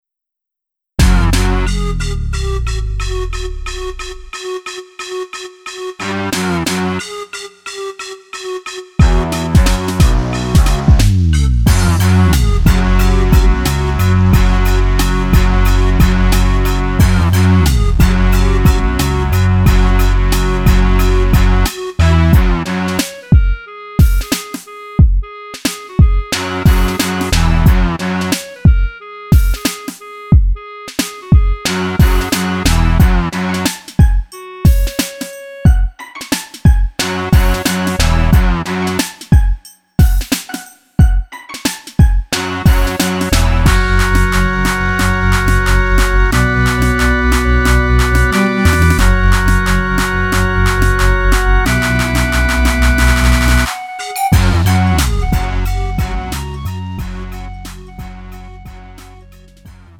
음정 원키 3:33
장르 가요 구분